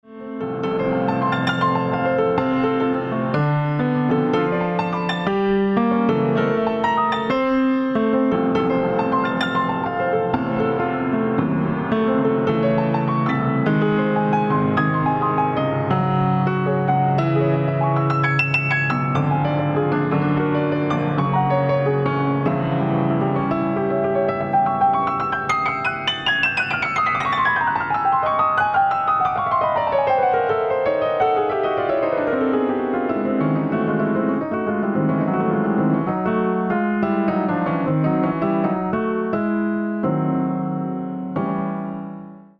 優雅な曲線を描くレトロモダンなデザインが、豊かで温かみのある音色を より鮮やかに彩ります。